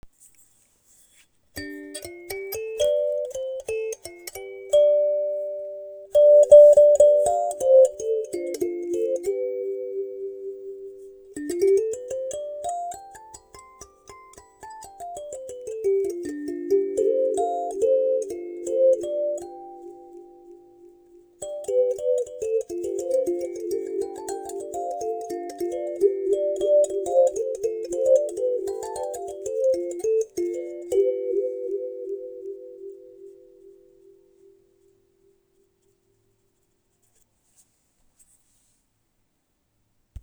L’instrument offre un son doux, enveloppant et méditatif, idéal pour :
La rencontre entre la vibration du métal et la profondeur minérale de la céramique crée une signature sonore singulière : chaude, organique et immersive.
• Instrument : sanza / kalimba artisanale
• Nombre de lames : 13
• Accordage : hexatonique myxolydien
• Résonateur : bol en céramique tourné main
bolimba20103.wav